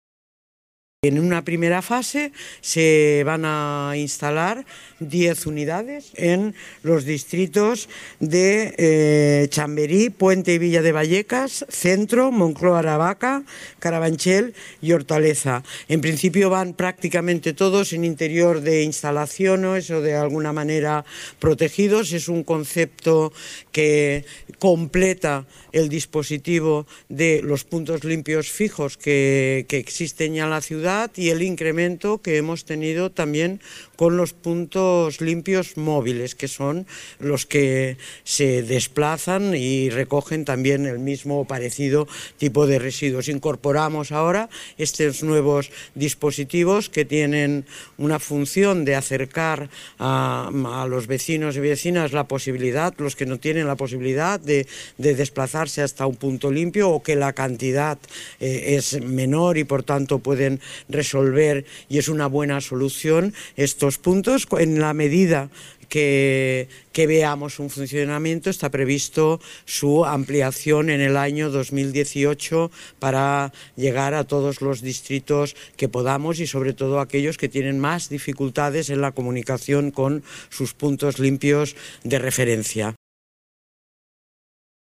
Inés Sabanés explica en qué lugares se van a instalar estos puntos limpios en una primera fase